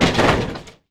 DEMOLISH_Metal_Quick_stereo.wav